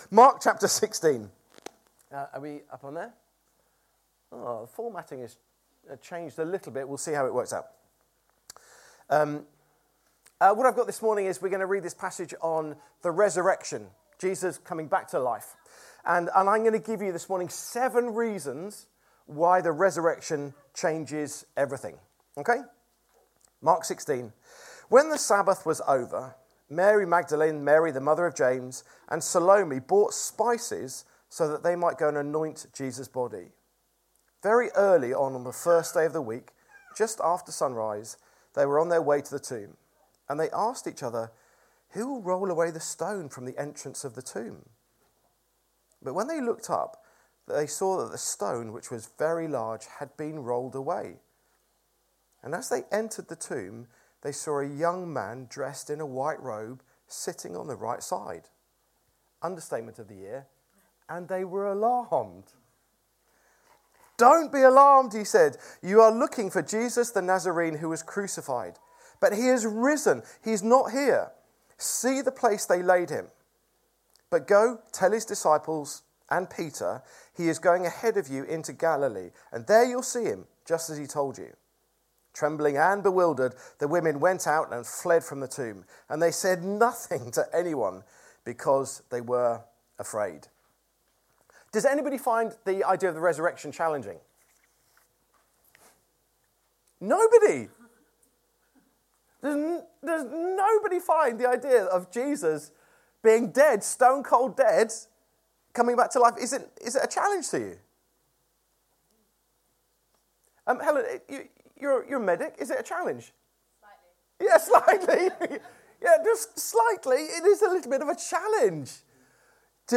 Download The Resurrection – He is alive! | Sermons at Trinity Church